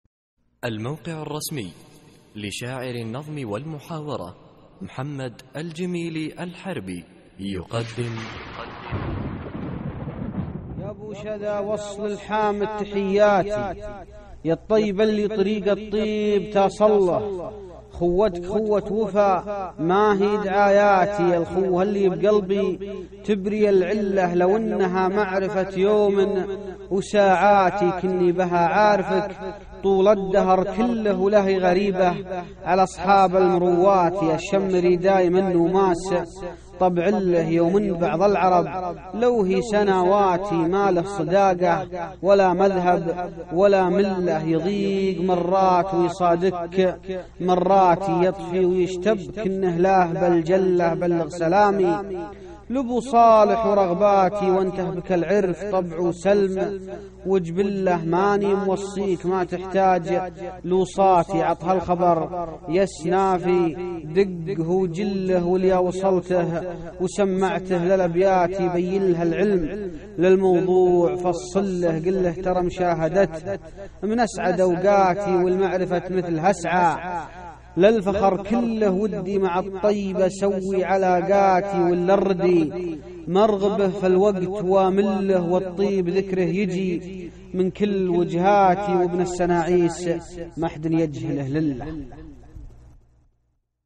القصـائــد الصوتية
اسم القصيدة : الخوة ~ إلقاء